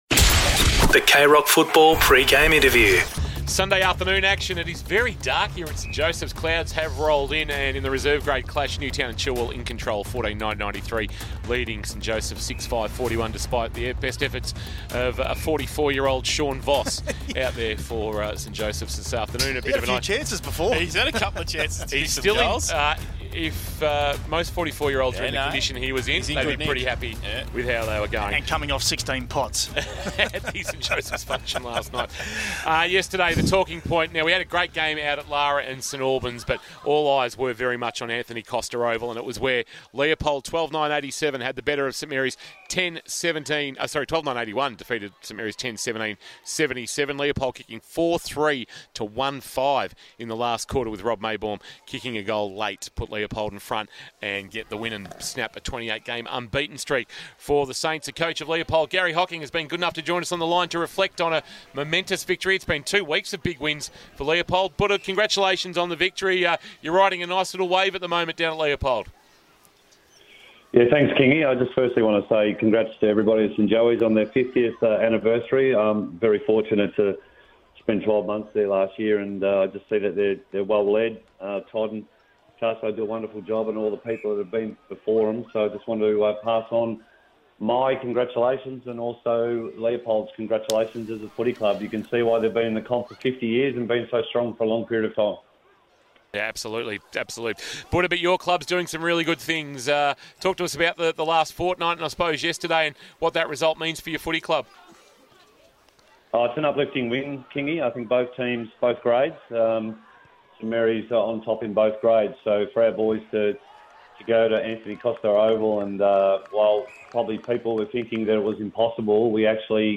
2022 - GFL - Round 10 - ST JOSEPH’S vs. NEWTOWN & CHILWELL: Pre-match Interview – Garry Hocking (Leopold Coach)